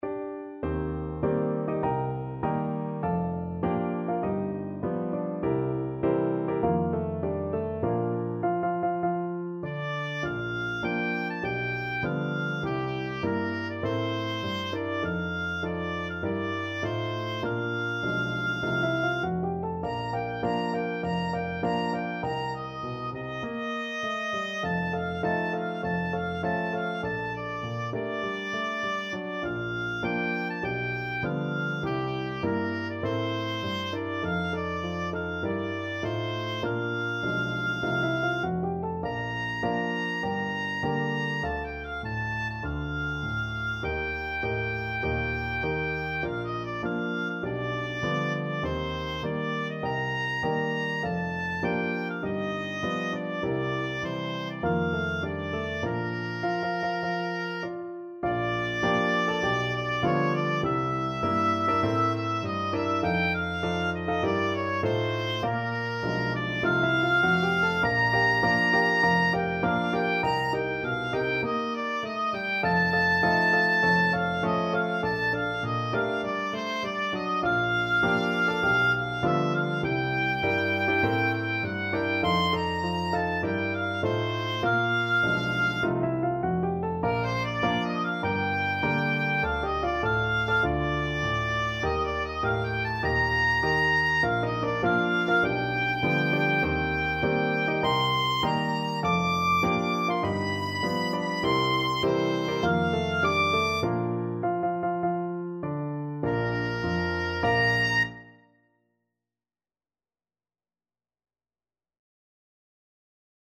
Oboe
Bb major (Sounding Pitch) (View more Bb major Music for Oboe )
Moderato = c. 100
4/4 (View more 4/4 Music)
G5-D7